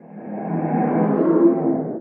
守卫者：低鸣
守卫者在水中空闲
Minecraft_guardian_ambient2.mp3